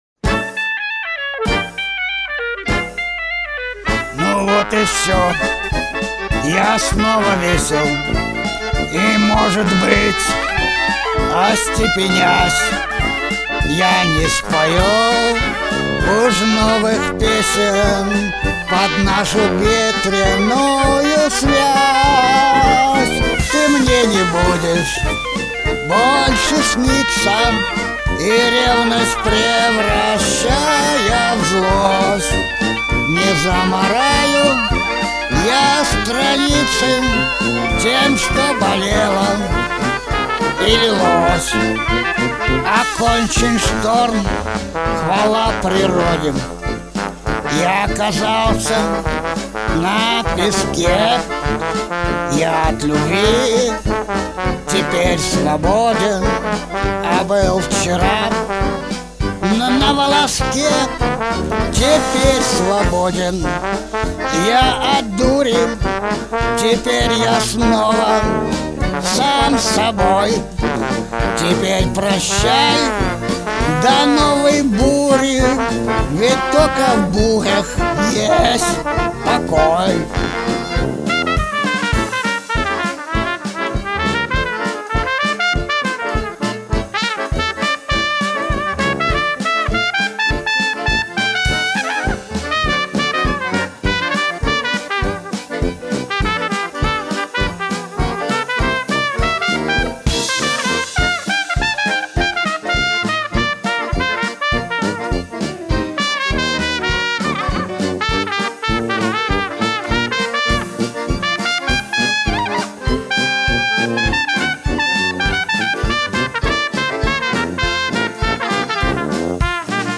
Старый одесский джаз